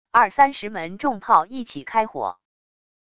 二三十门重炮一起开火wav音频